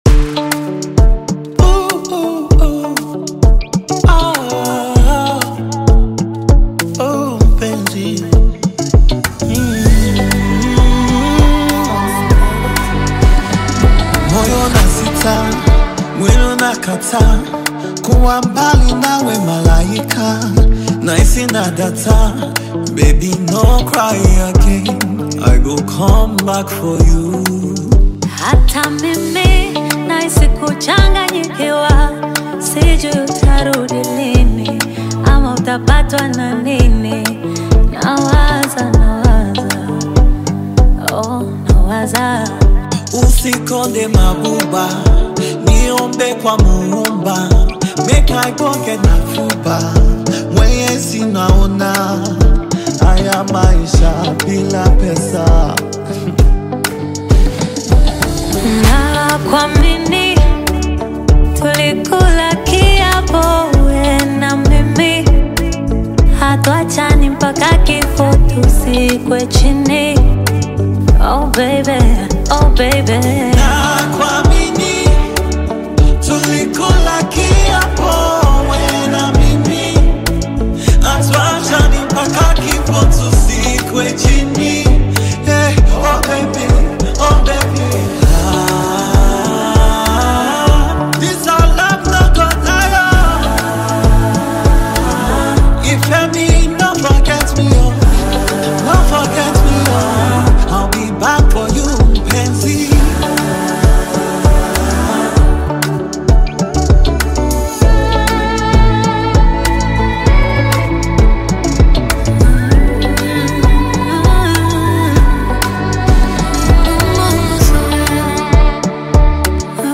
powerful and expressive vocals
melodic delivery